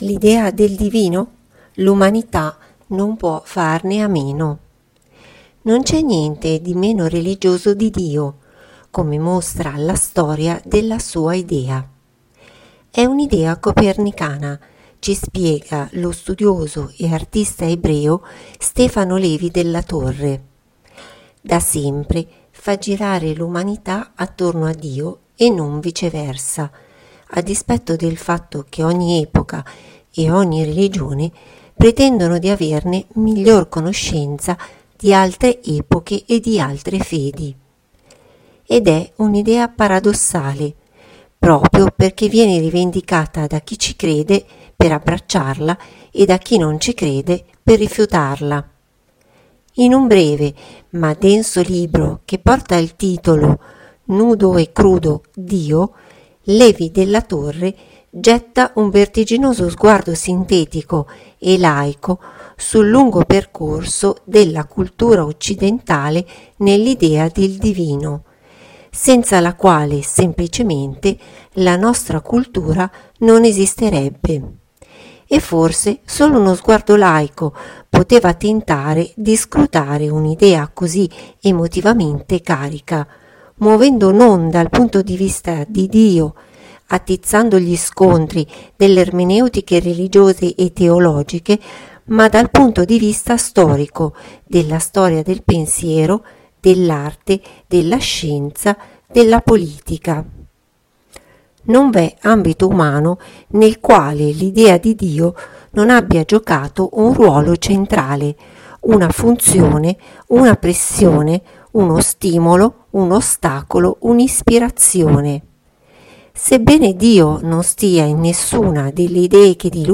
L’articolo si sviluppa intorno all’idea di Dio, traendo spunto da un breve ma denso libro che porta il titolo nudo e crudo “Dio”, dove l’autore, Levi Della Torre, getta un vertiginoso sguardo sintetico, e laico, sul lungo percorso della cultura occidentale nell’idea del divino, senza la quale semplicemente la nostra cultura non esisterebbe. Ma ascoltiamone le lettura.